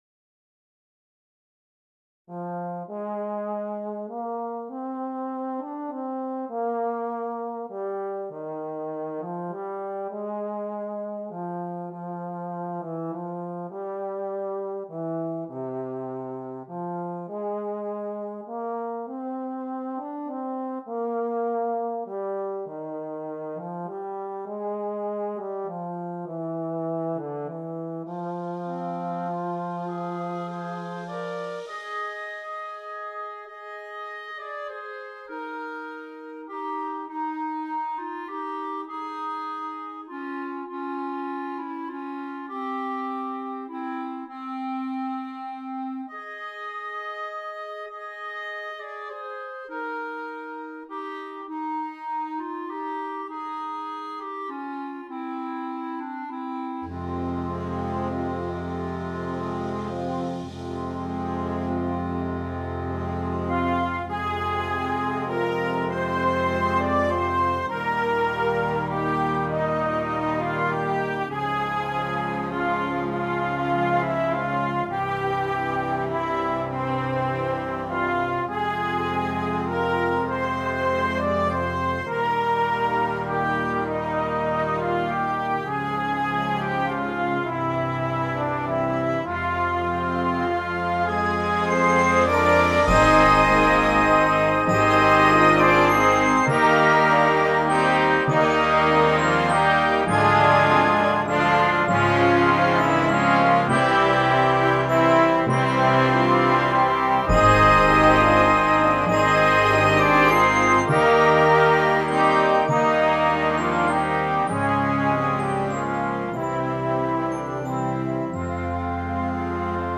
Concert Band
Traditional
This work is full of contrasts between the sweetly lyrical